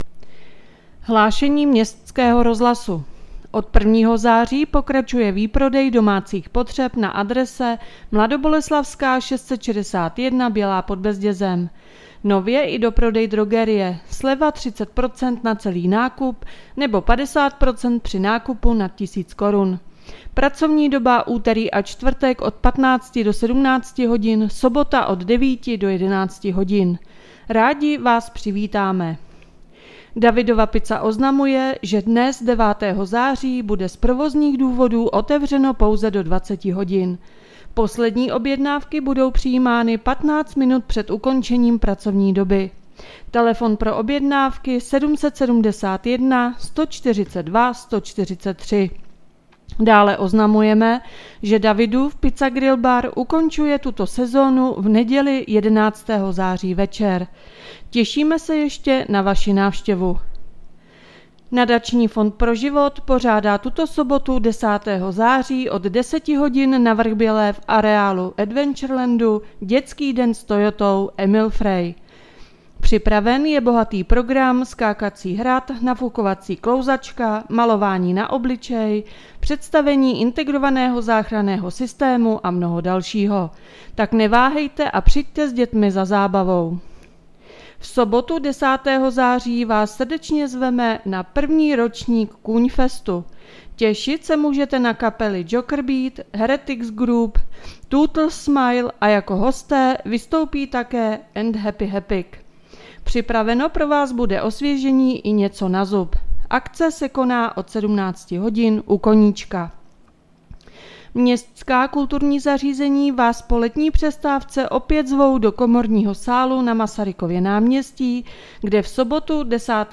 Hlášení městského rozhlasu 9.9.2022